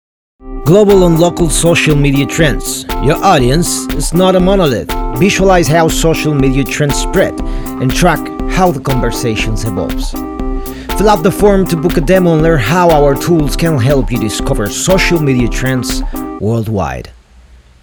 Annonces